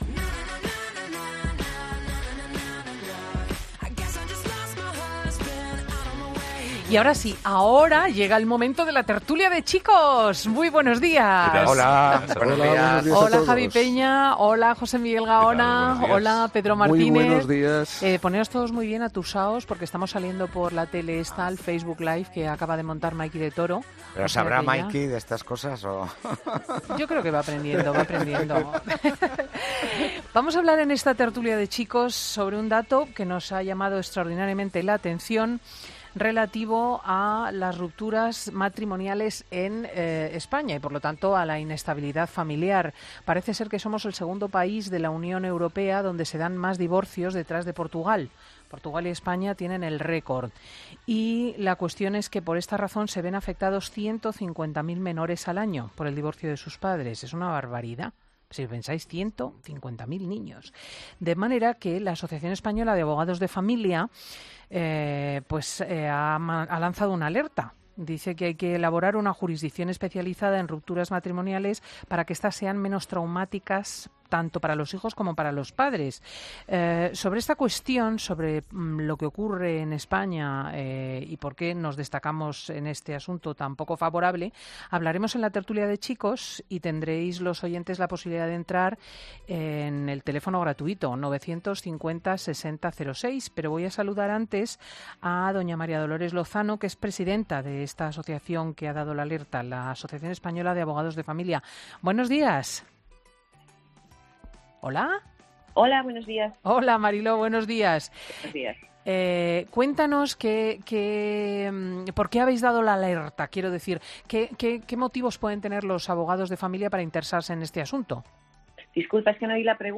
Escucha aquí la Tertulia de Chicos: